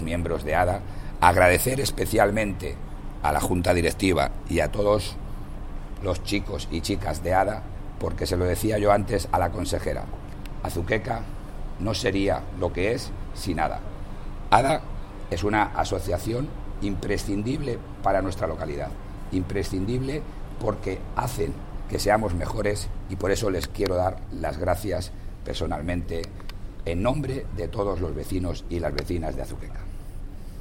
Alcalde Azuqueca de Henares: declaraciones ADA